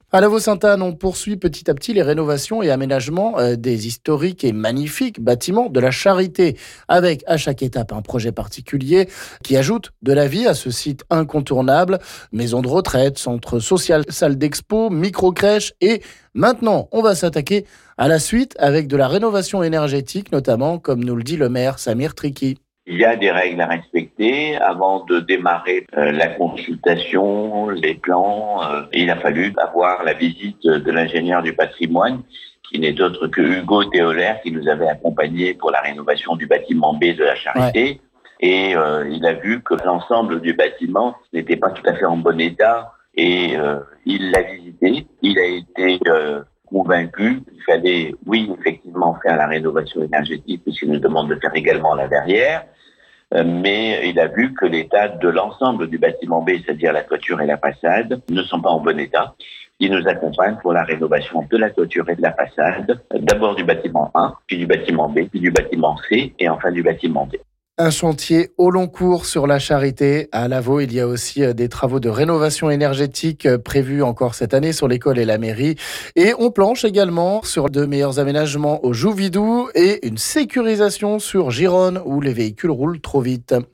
Le maire de Lavault-Ste-Anne nous en parle...